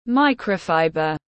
Vải sợi tổng hợp siêu mịn tiếng anh gọi là microfiber, phiên âm tiếng anh đọc là /ˈmaɪkrəʊfaɪbər/.
Microfiber /ˈmaɪkrəʊfaɪbər/